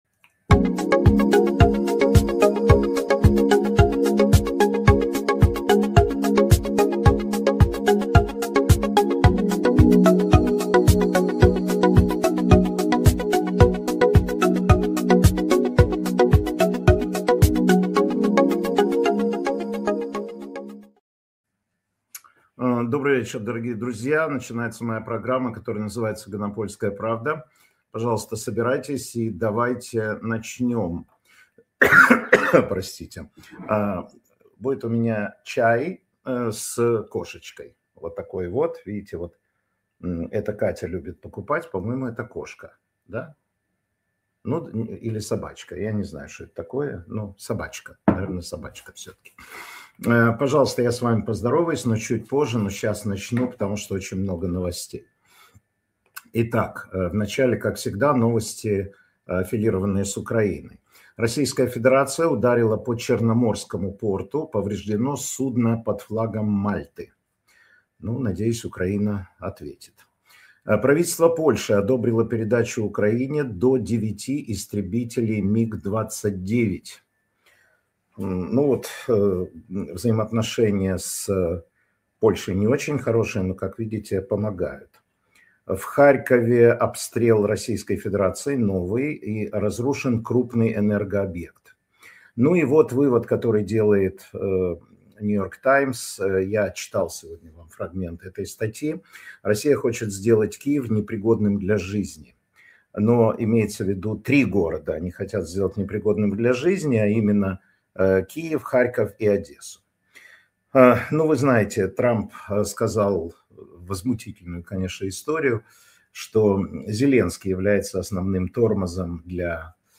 Информационно-аналитическая программа Матвея Ганапольского